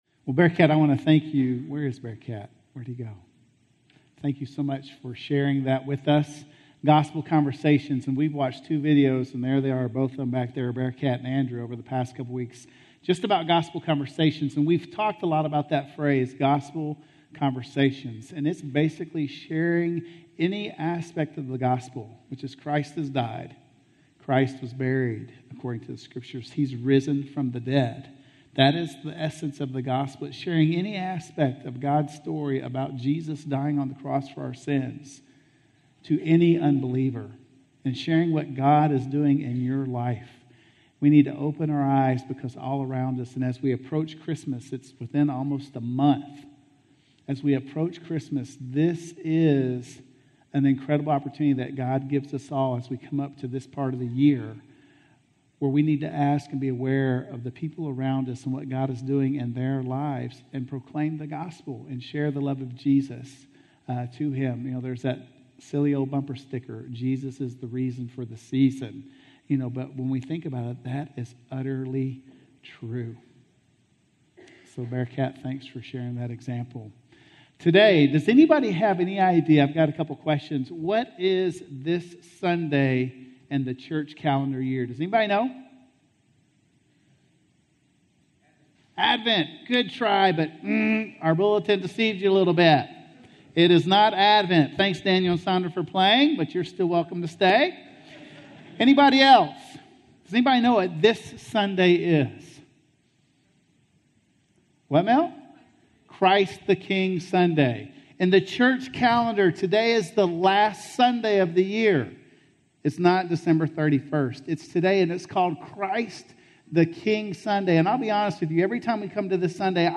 The Once and Future King - Sermon - Woodbine